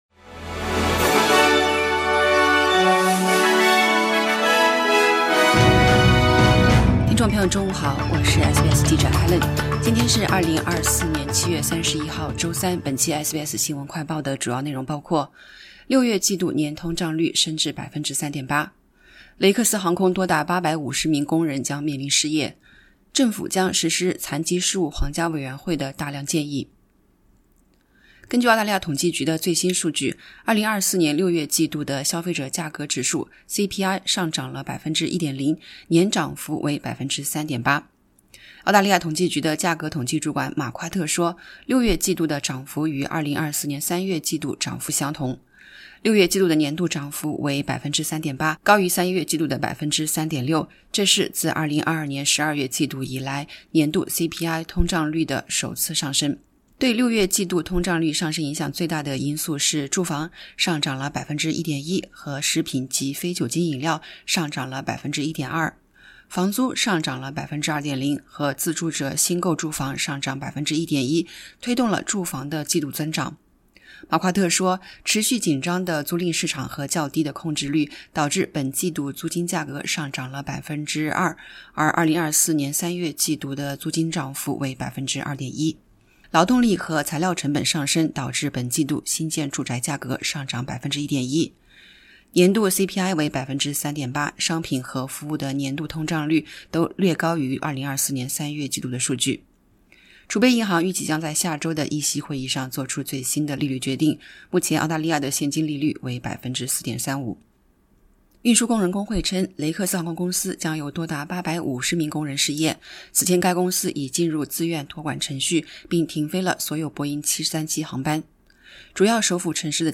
SBS News Flash in Mandarin: CPI rose 1.0% in the June 2024 quarter Credit: PA/Alamy